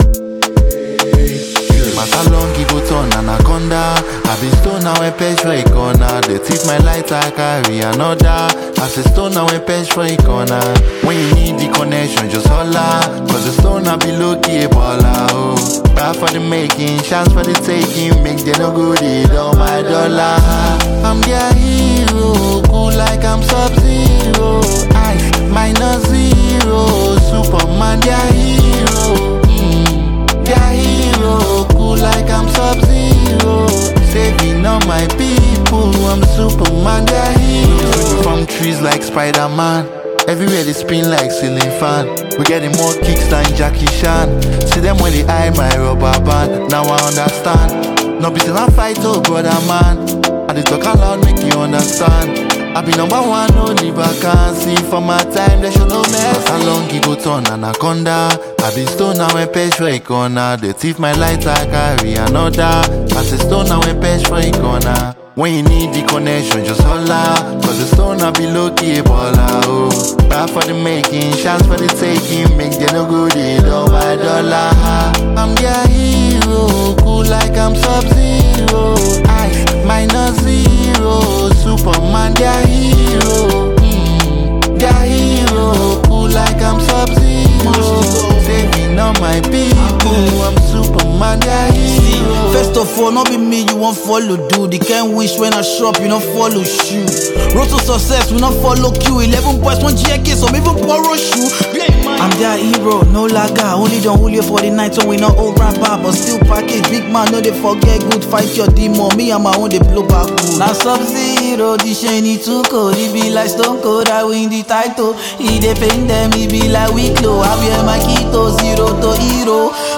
Afrofusion
stands out as a motivational anthem